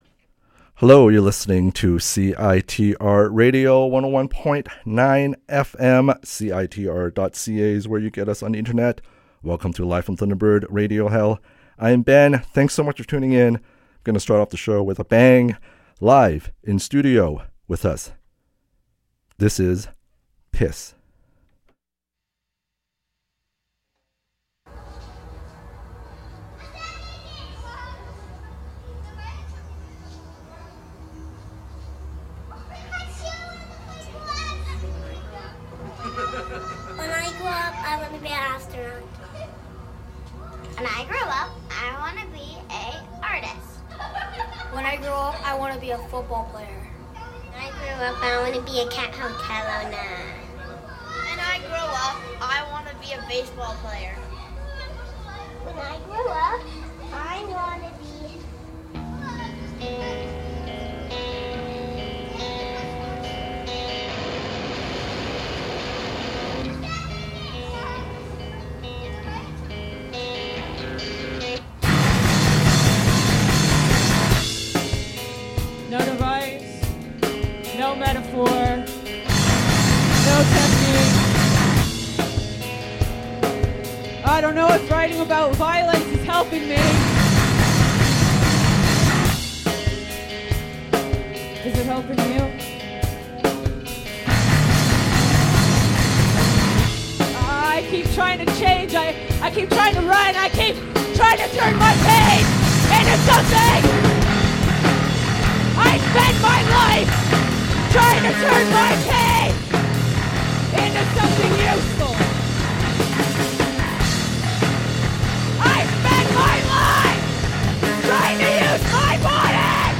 Live in studio performance